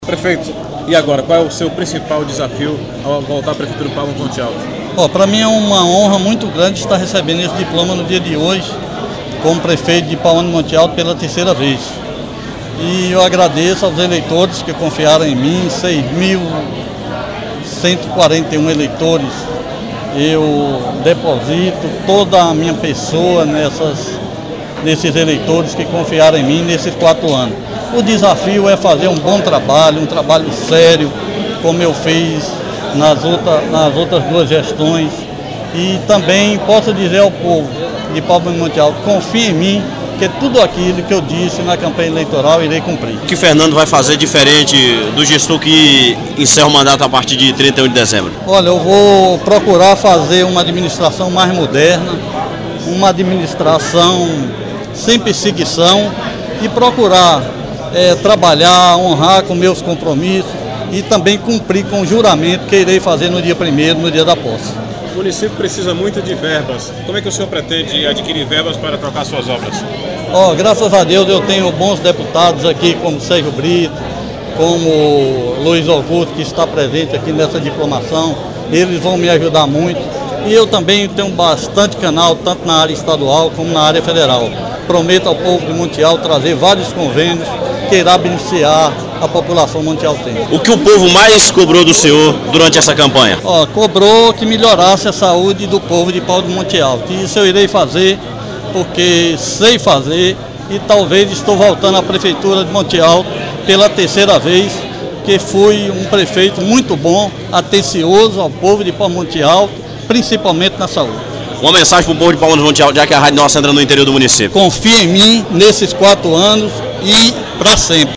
O ato solene aconteceu na tarde desta quinta-feira, 13 de dezembro.